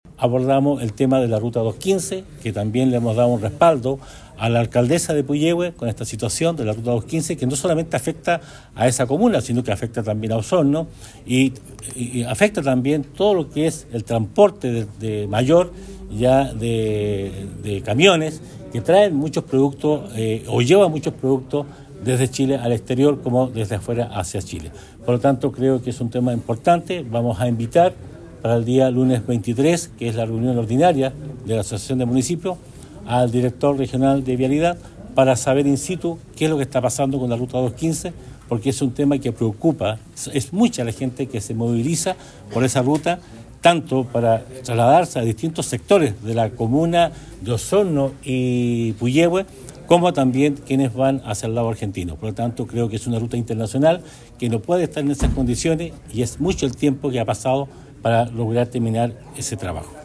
El Alcalde de Osorno, y Presidente de la Asociación de Municipalidades de la Provincia, Emeterio Carrillo, indicó que esta es una temática que se debe abordar en conjunto, pues sus condiciones afectan a todo el territorio.